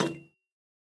Minecraft Version Minecraft Version 25w18a Latest Release | Latest Snapshot 25w18a / assets / minecraft / sounds / block / copper_bulb / step3.ogg Compare With Compare With Latest Release | Latest Snapshot